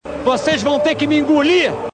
Mário Jorge Lobo Zagallo esbravejando durante a Copa América 1997 e falando uma frase que virou meme: vocês vão ter que me engolir!